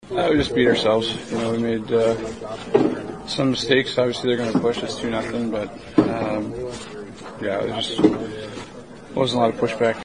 Sidney Crosby agreed with his coach that the Penguins didn’t answer the bell in the third period.